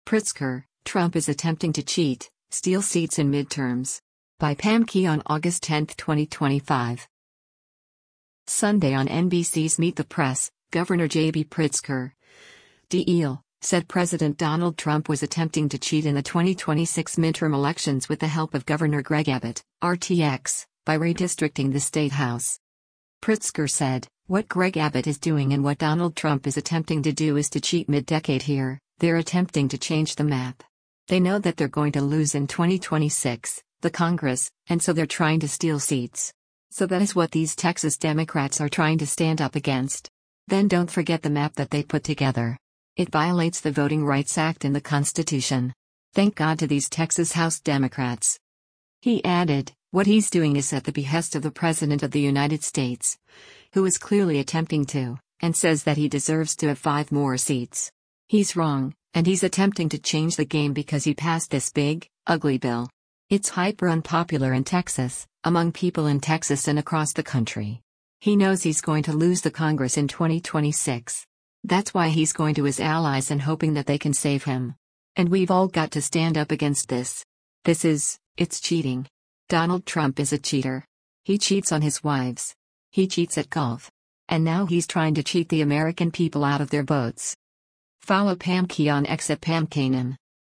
Sunday on NBC’s “Meet the Press,” Gov. J.B. Pritzker (D-IL) said President Donald Trump was attempting to cheat in the 2026 midterm elections with the help of Gov. Greg Abbott (R-TX) by redistricting the state house.